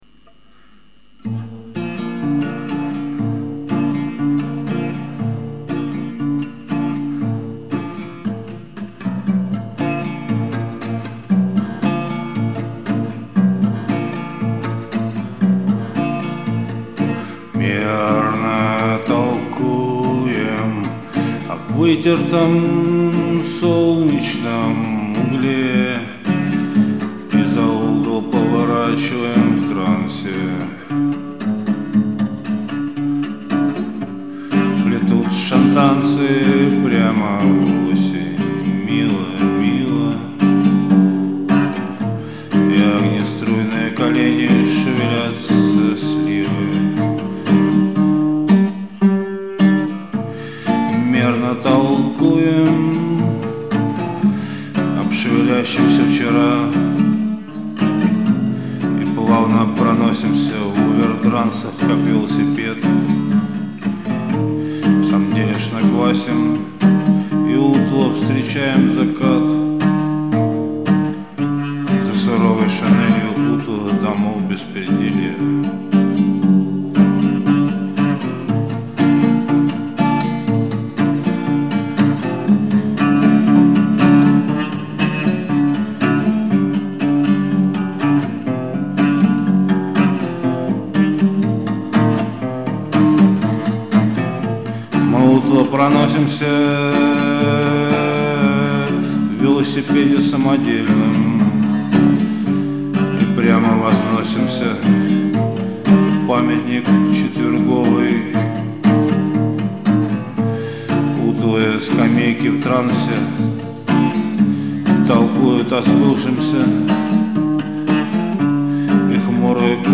----песни мои---------------------------------
летом записывал, ((2024г)